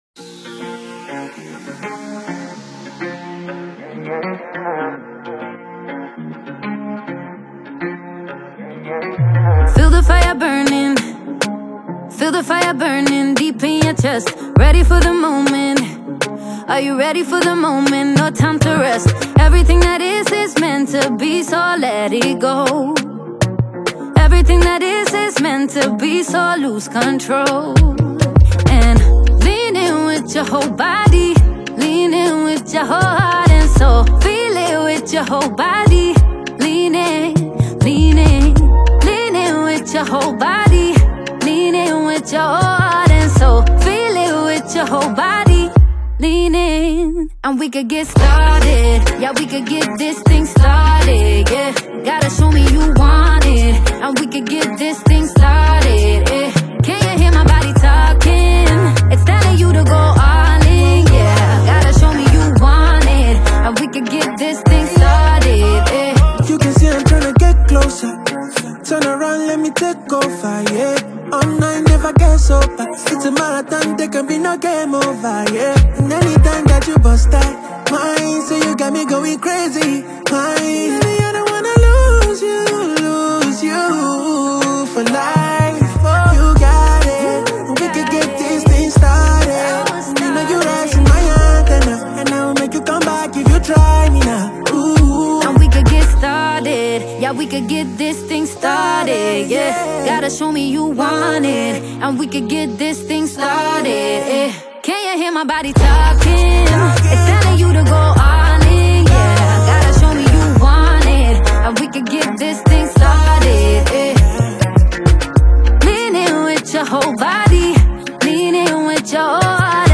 single